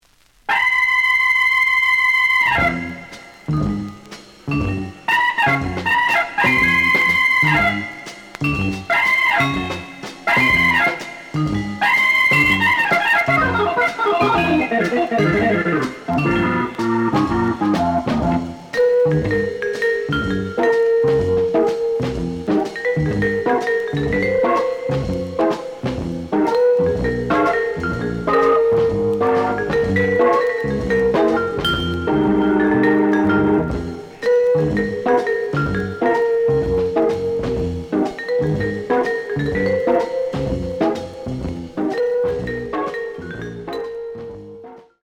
The audio sample is recorded from the actual item.
●Genre: Jazz Other
Looks good, but slight noise on both sides.)